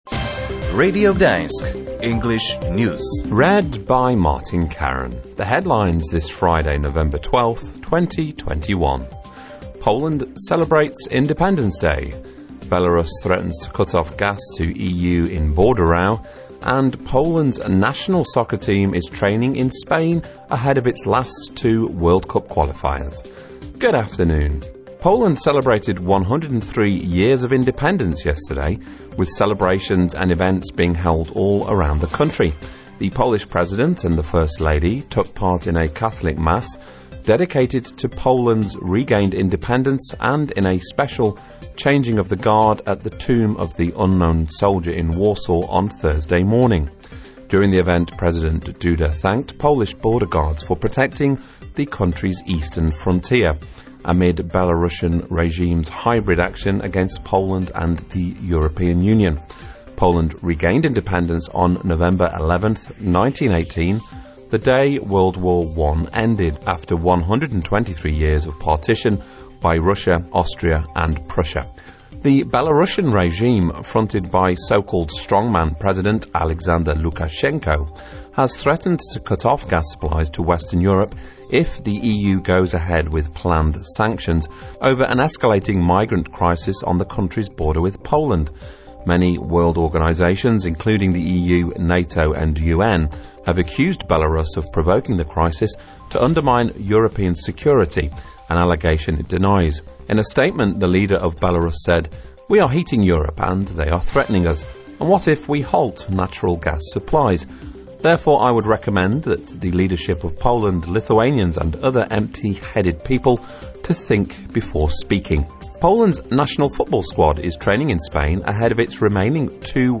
Radio Gdansk News in English: Poland celebrates Independence Day